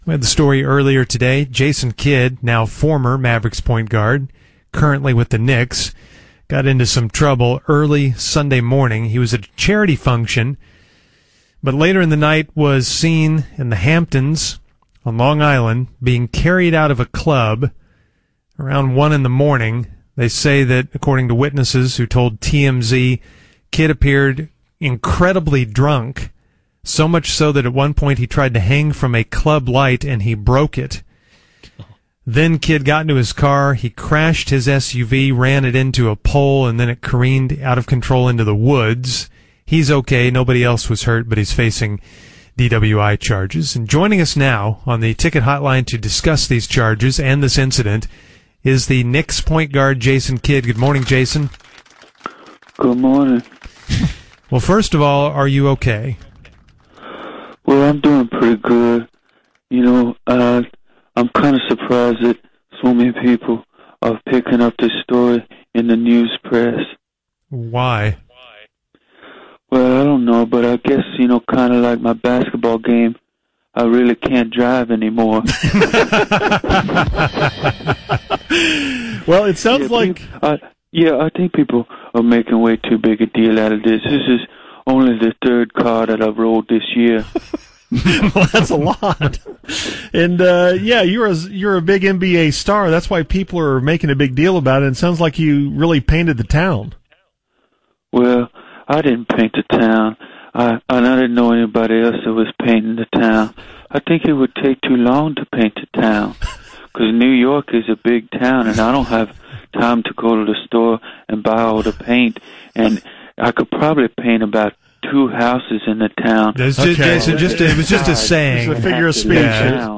Fake Jason Kidd Talks About His DWI